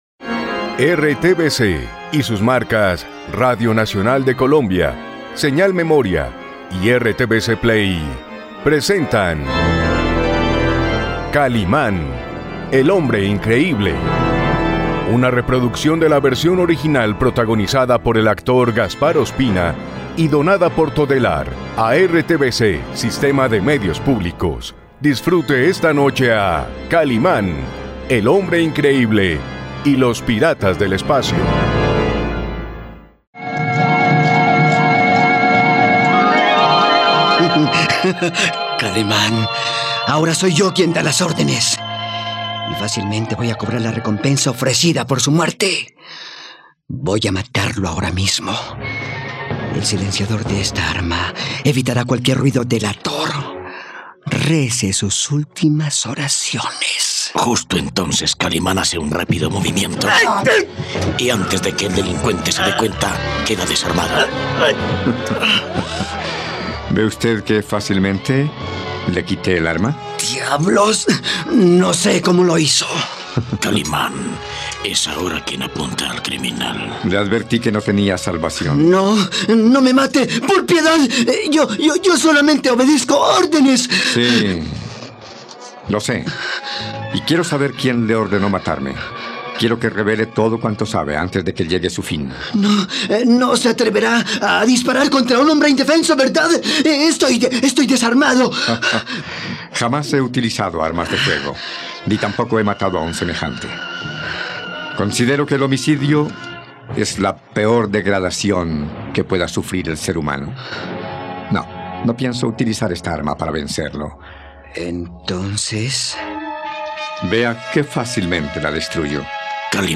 No te pierdas la radionovela de Kalimán y los piratas del espacio aquí, en RTVCPlay.